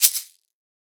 West MetroPerc (35).wav